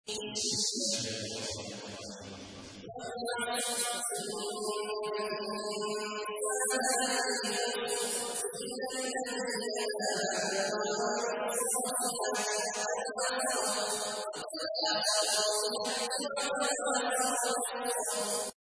تحميل : 103. سورة العصر / القارئ عبد الله عواد الجهني / القرآن الكريم / موقع يا حسين